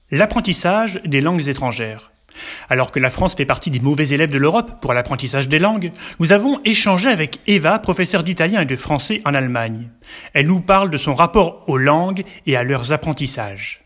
L’apprentissage des langues étrangères (Interview)